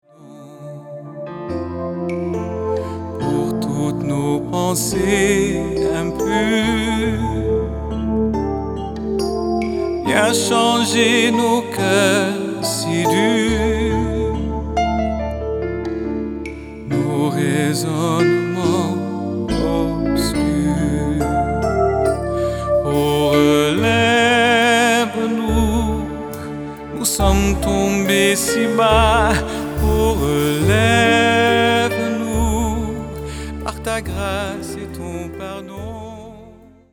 production 100% studio
Ces chants de style très variés
Format :MP3 256Kbps Stéréo